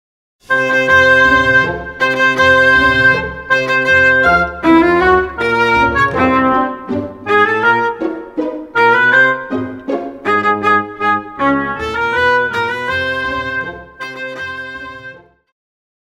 Pop
Viola
Orchestra
Instrumental
World Music,Fusion
Only backing